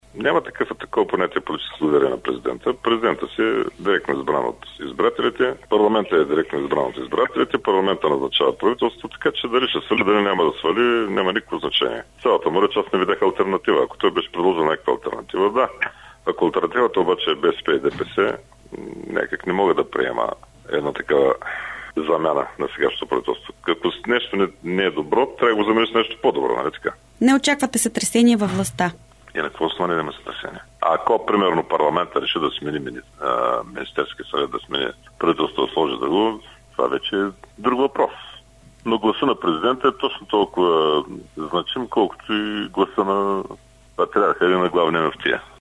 Чуйте още от думите на Симеонов пред Дарик